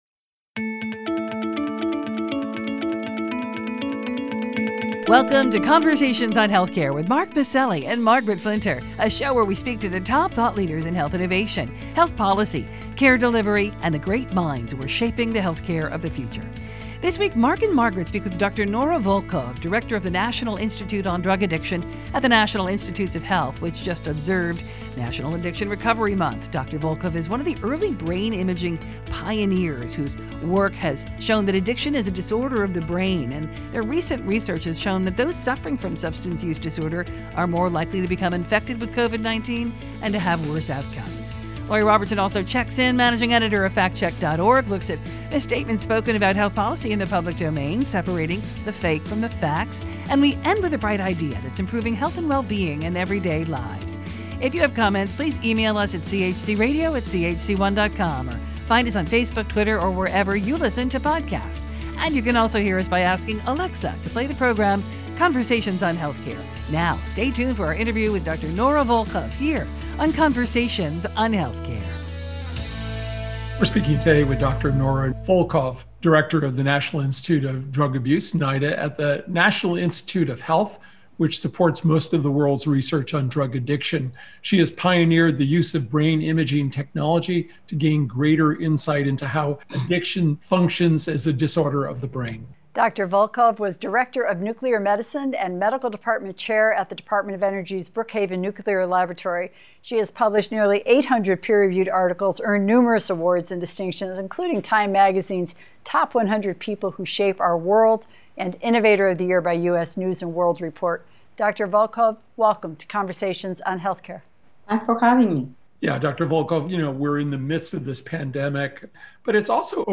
speak with Dr. Nora Volkow, Director of the National Institute on Drug Abuse at the National Institutes of Health. Dr. Volkow discusses her pioneering work in brain imaging which has given us a window into how addiction works in the brain. She discusses her recent report on susceptibility to COVID-19 for those with Substance Use Disorder, her concerns about increase in overdoses during the pandemic, and the need to curtail the vaping industry which is leading to a dangerous spike in nicotine addiction among young adults.